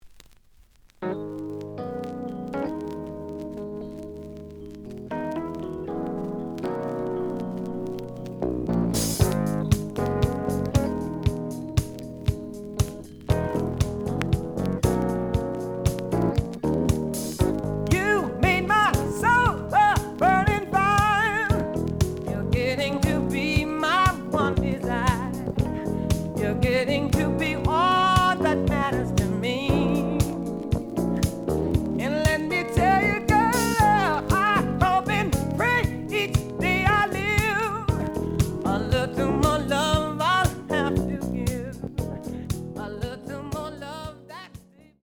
The audio sample is recorded from the actual item.
●Format: 7 inch
●Genre: Soul, 80's / 90's Soul
Slight edge warp.